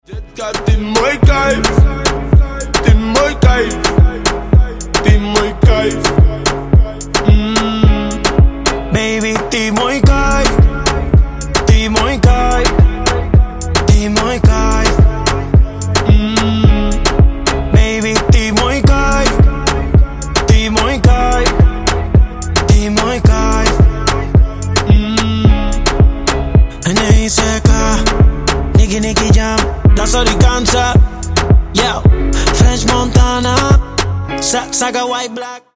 Латинские Рингтоны
Рингтоны Ремиксы » # Поп Рингтоны